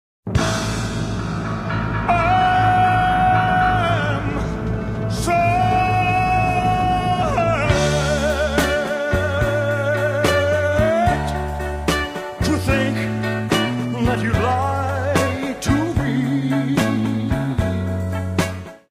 Short, relatively low-quality sound sample
soul ballad